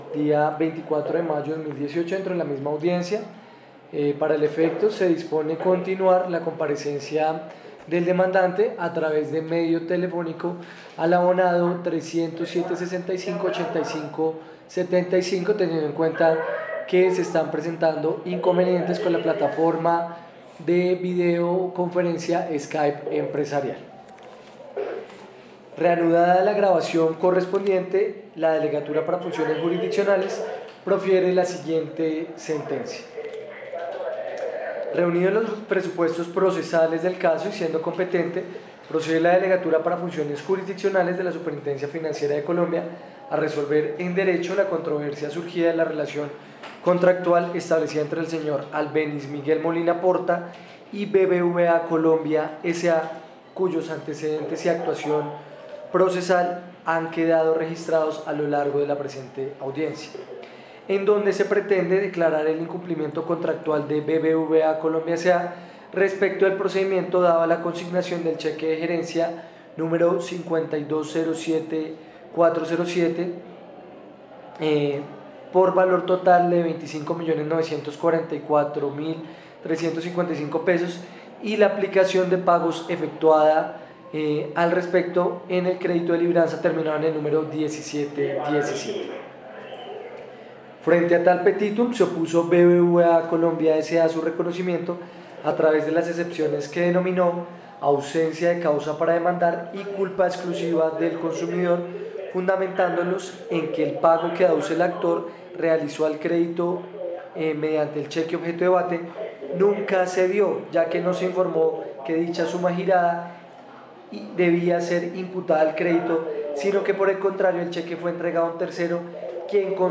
Suscríbete y escucha las noticias jurídicas narradas con IA.